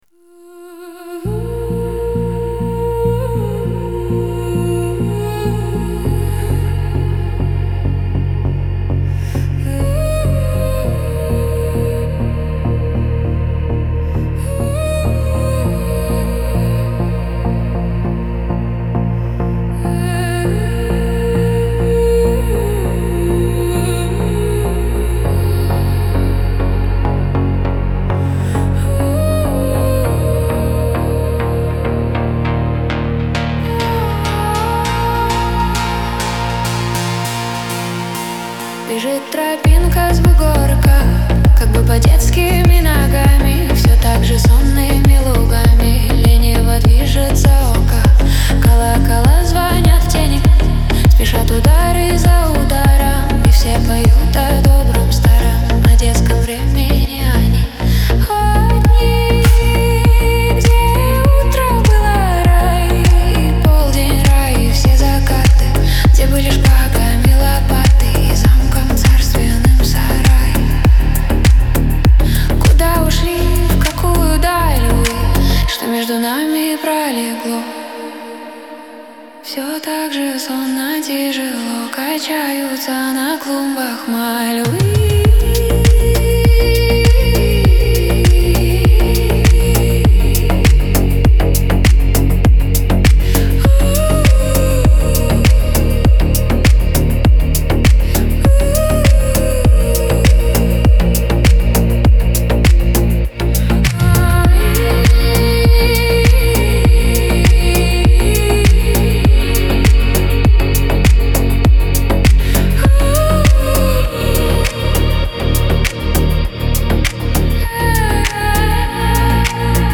СПОКОЙНАЯ МУЗЫКА